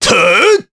Crow-Vox_Attack4_jp.wav